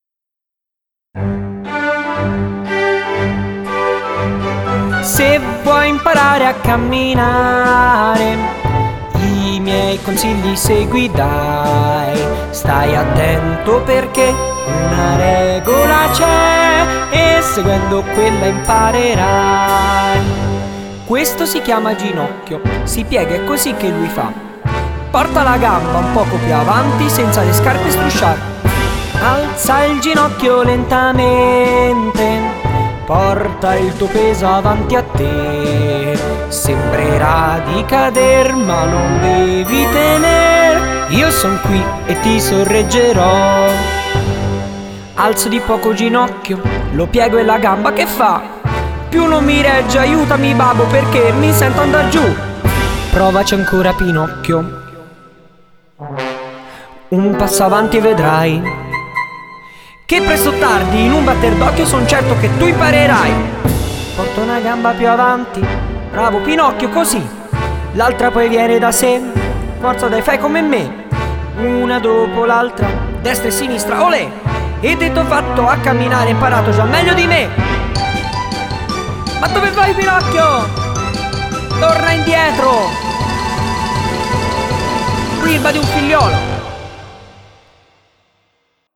Il risultato è una commedia musicale per bambini con i toni, le caratteristiche stilistiche e musicali di un vero musical.